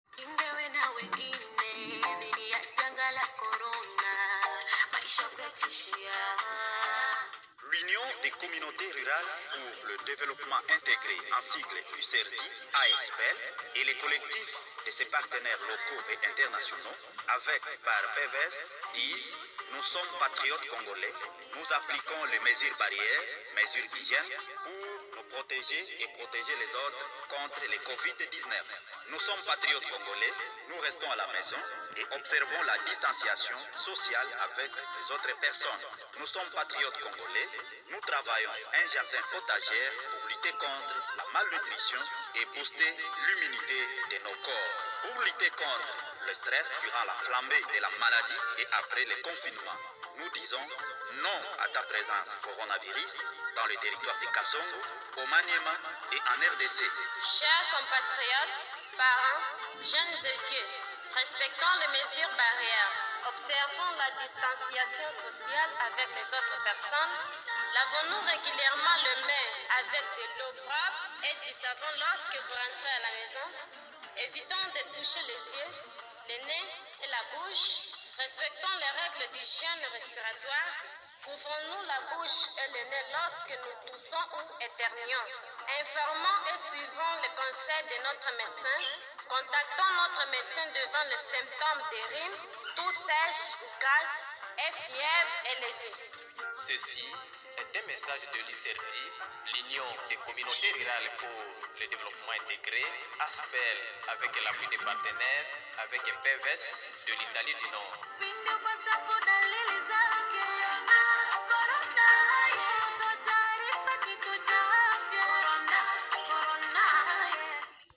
e messaggi radio.
spot_pub_congo.aac